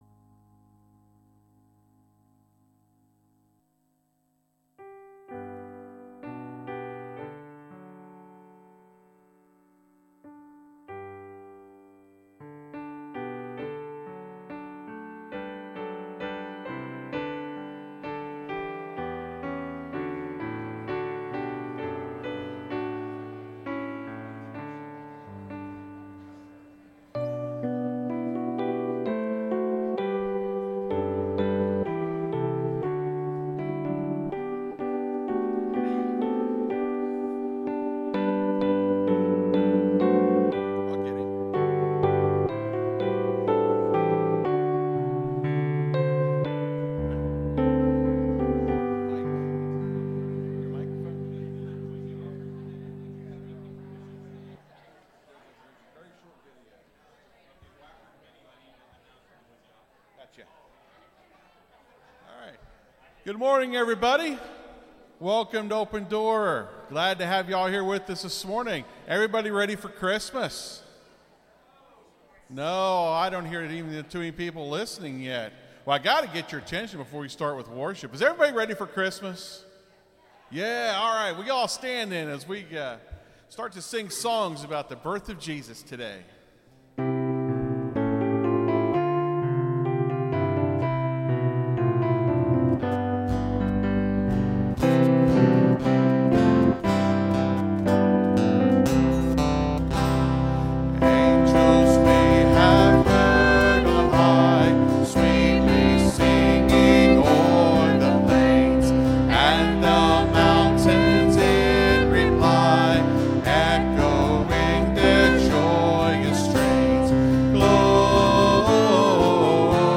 (Sermon starts at 26:00 in the recording).